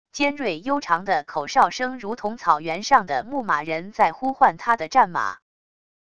尖锐悠长的口哨声如同草原上的牧马人在呼唤他的战马wav音频